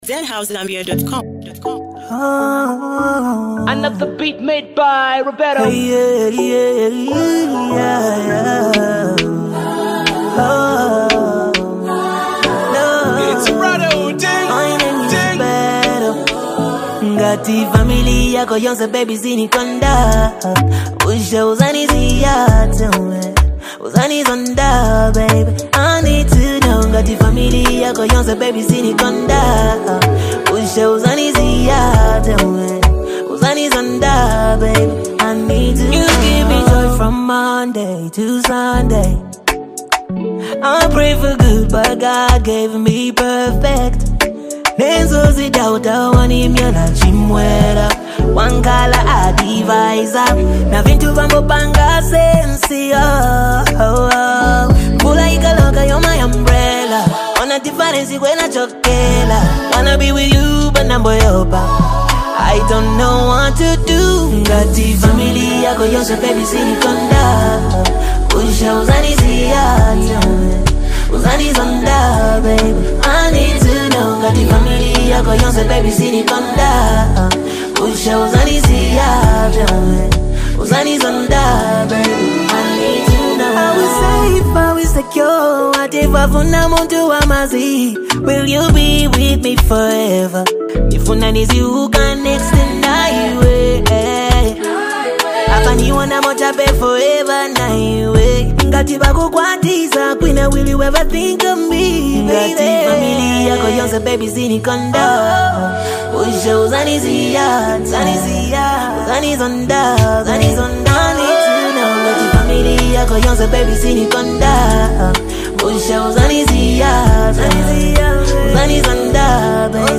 a catchy and emotional track that questions love